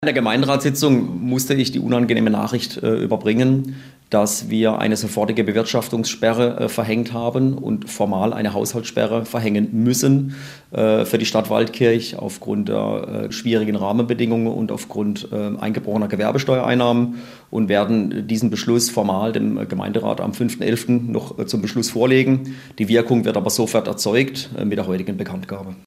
Gemeinderatsitzung in Waldkirch am 22. Oktober 2025
Oberbürgermeister Michael Schmieder verkündet im Waldkircher Gemeinderat die Haushaltssperre.